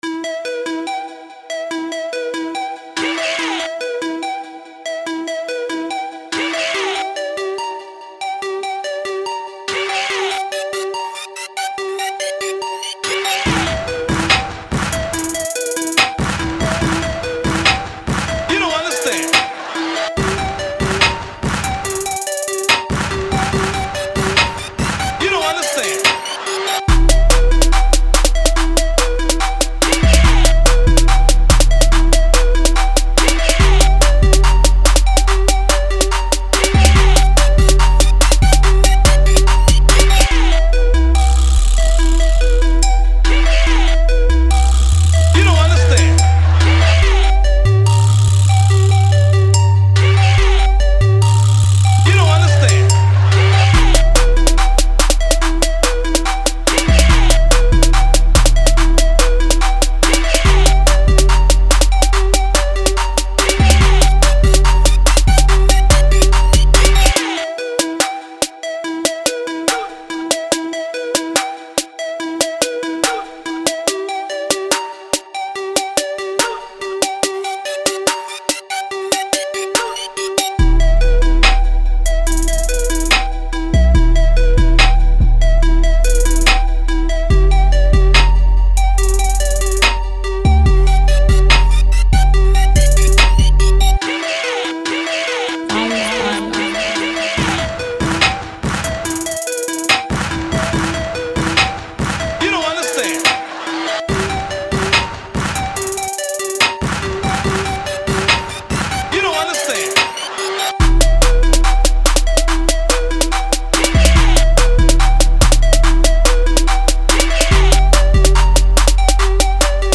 free instrumentals